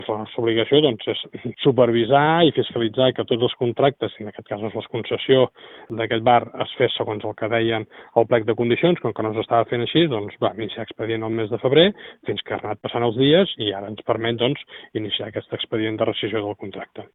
Així ho ha confirmat en declaracions a Ràdio Calella TV: